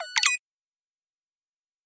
Электронный звук отправленного сообщения